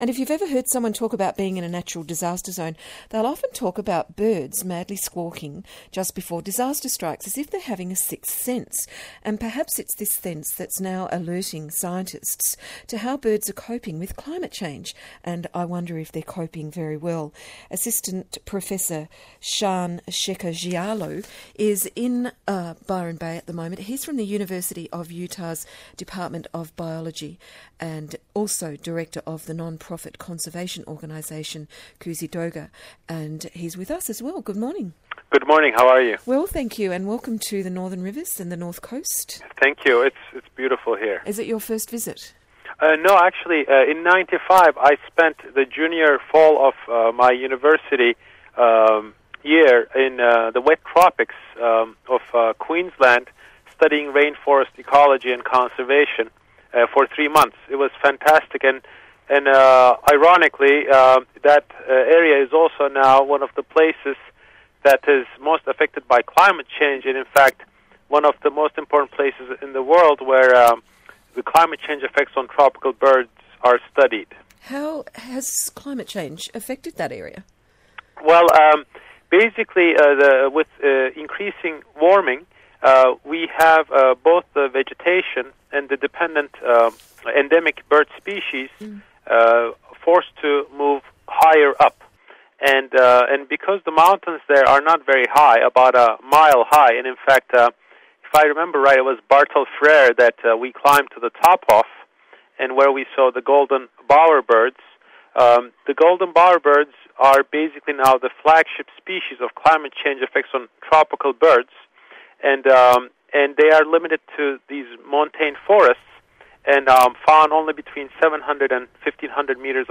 You can listen to this ABC interview here.